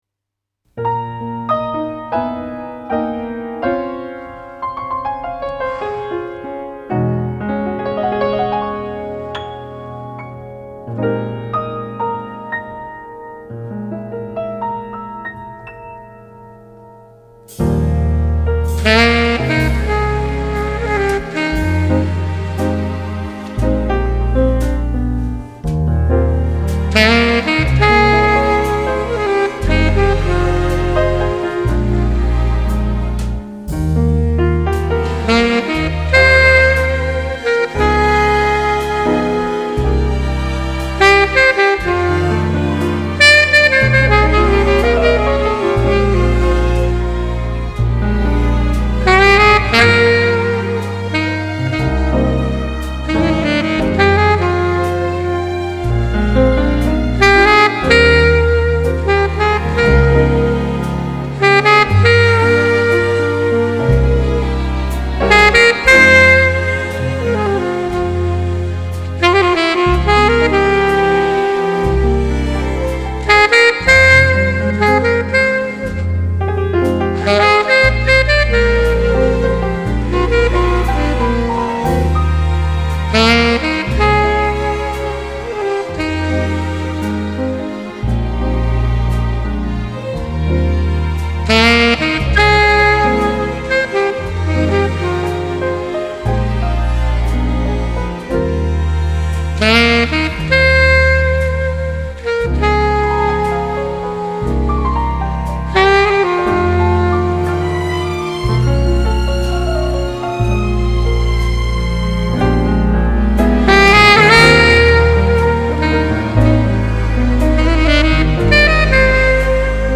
que j’ai enregistré au saxophone alto